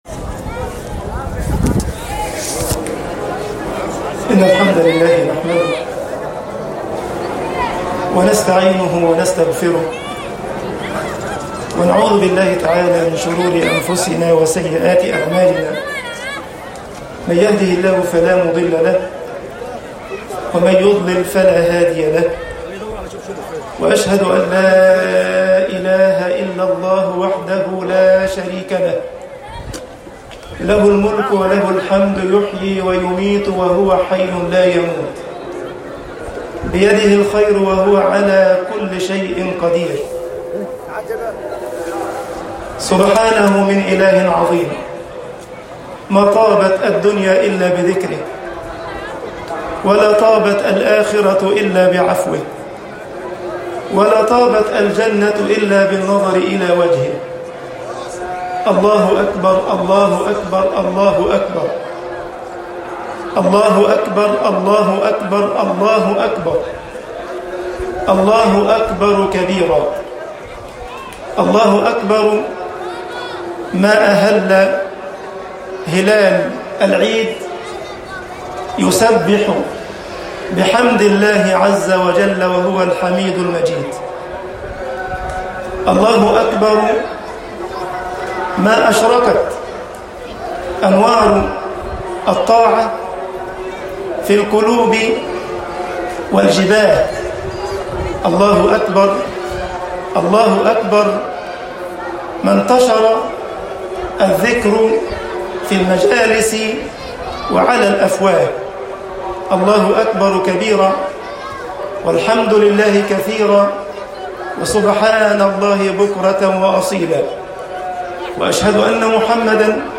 خطبة عيد الأضحى